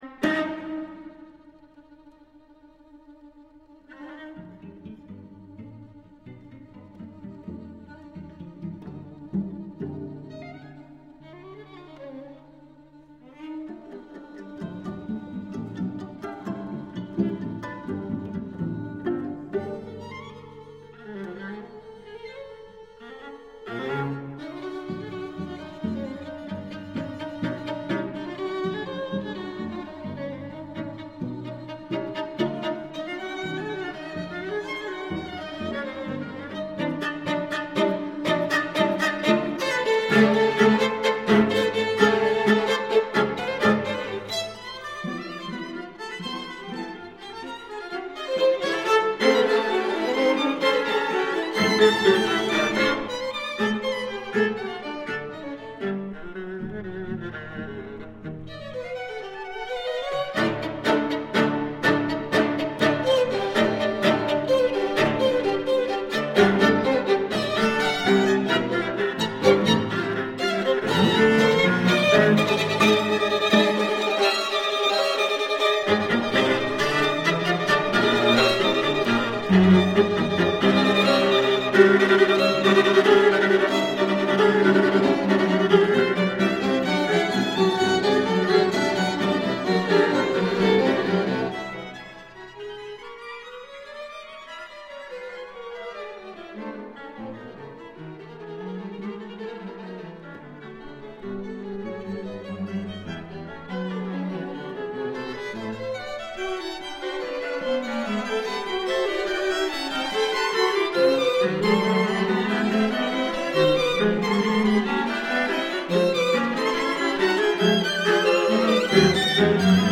有人曾这样评价这六首曲子“他们混合了刺耳而令人难以接受的和弦、无调性音乐、相当传统的悦耳的和声”
演奏粗犷有力，音色却不失柔美、娇艳。